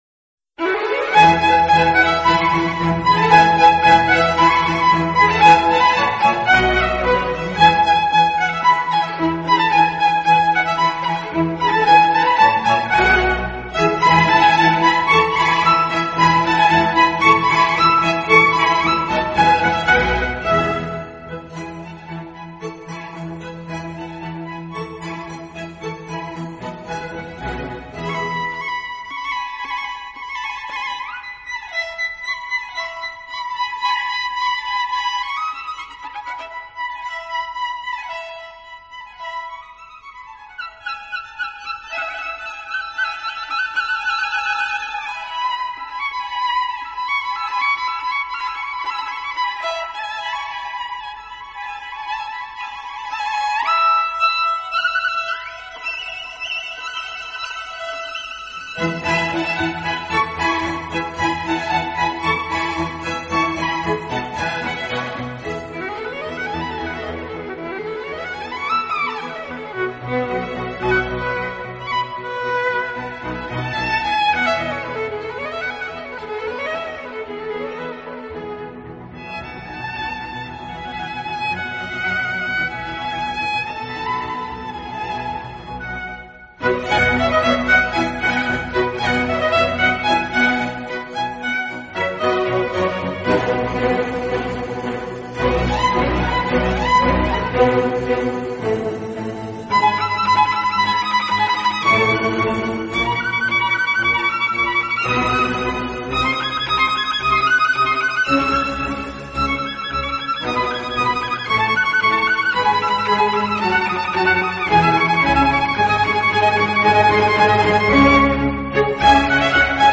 音乐类型：Classical